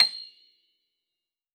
53q-pno26-C6.wav